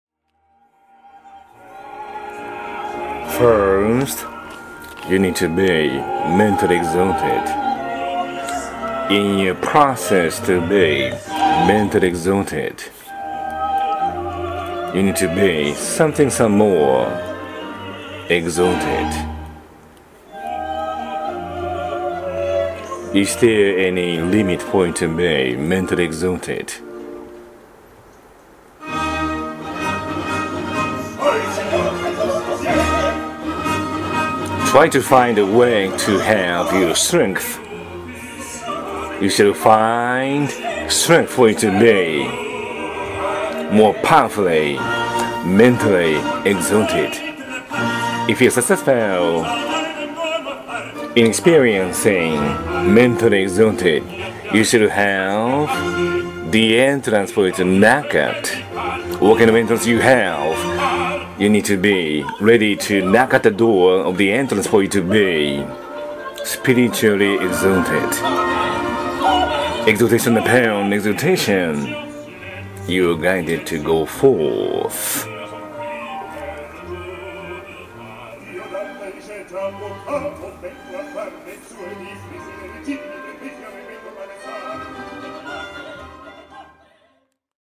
—”lecture dramatized” in the opera—